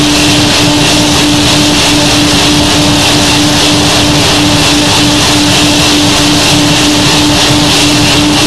vulcan-howel.wav